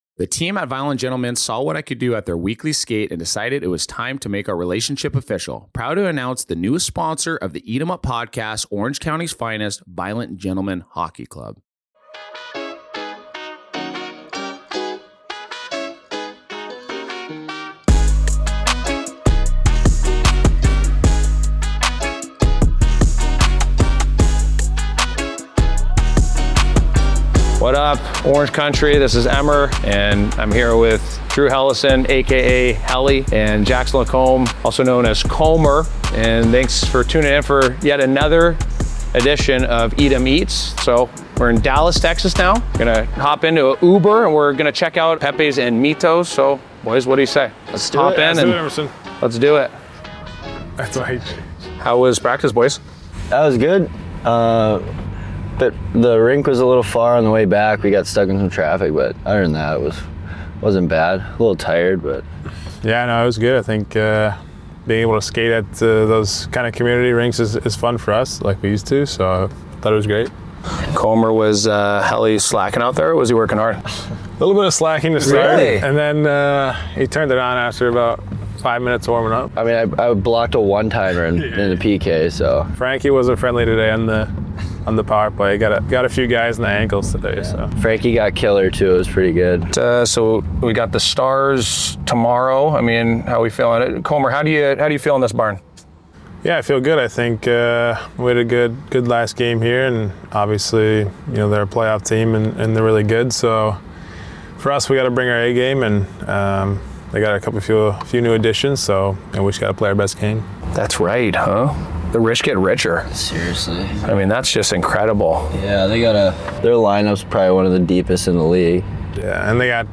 Former NHL player and current Anaheim Ducks broadcaster, Emerson Etem, hosts his own show that features fan voicemails, hot takes, quirky topics and much more!